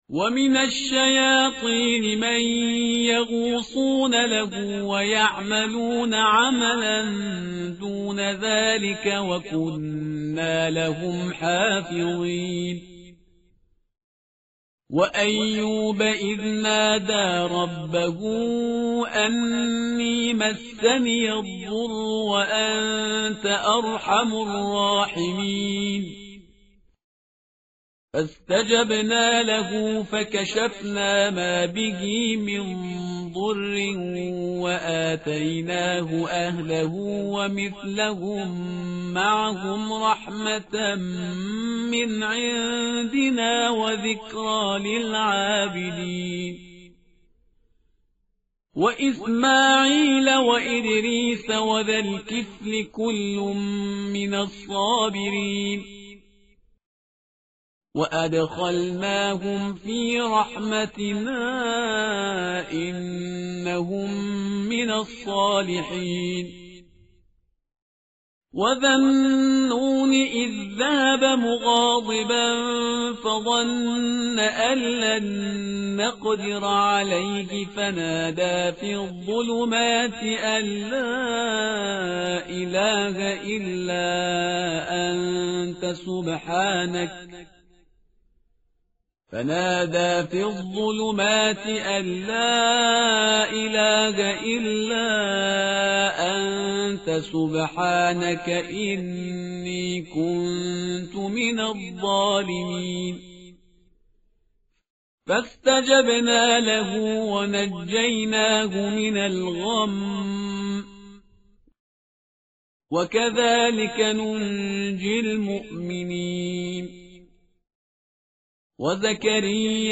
متن قرآن همراه باتلاوت قرآن و ترجمه
tartil_parhizgar_page_329.mp3